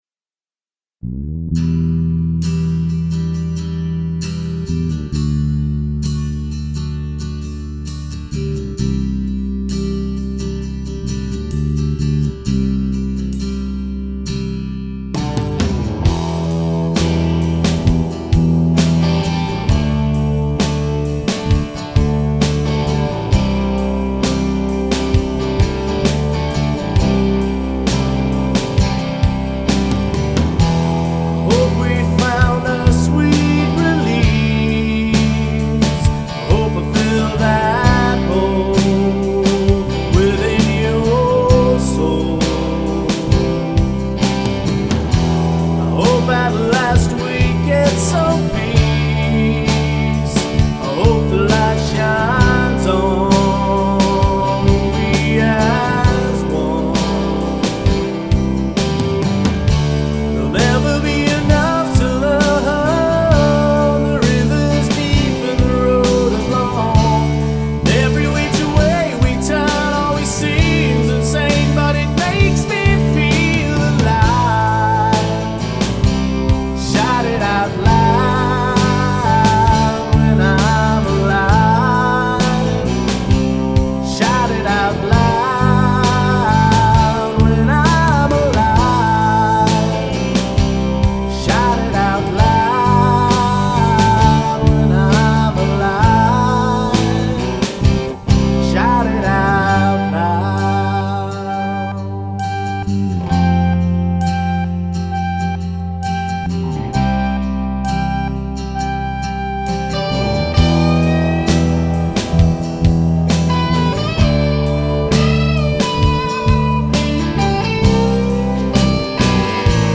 Home/Studio Recordings